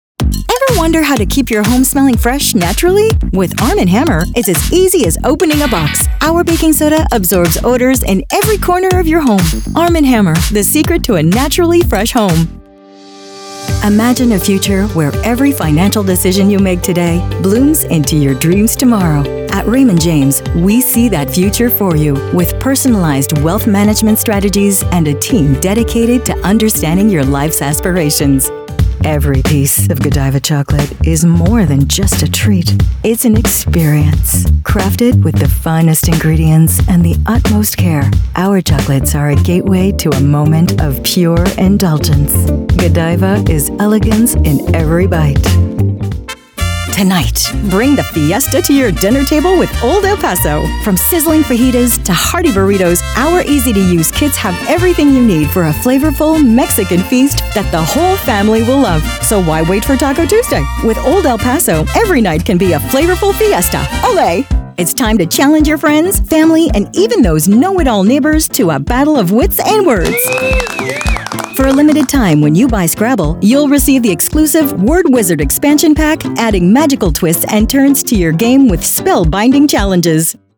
Commercial demo (EN)
English - USA and Canada